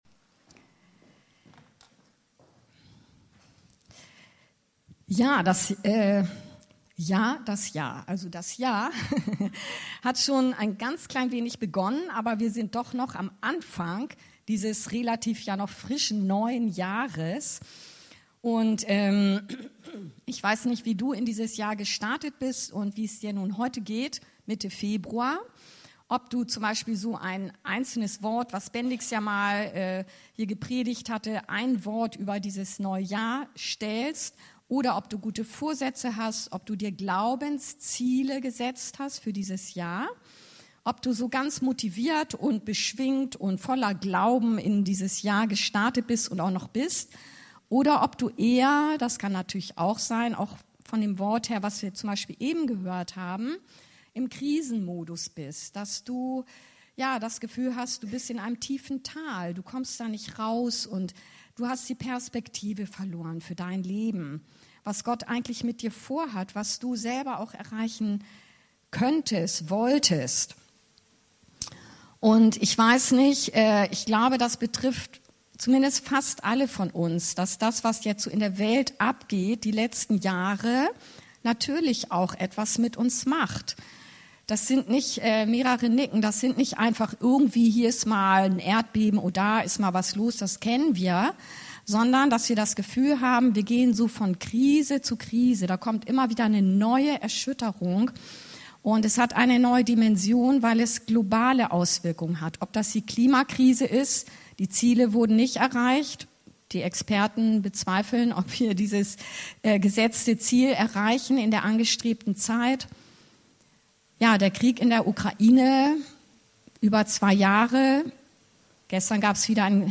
Leben mit Glaubensperspektive Hebr.12,1b-3 ~ Anskar-Kirche Hamburg- Predigten Podcast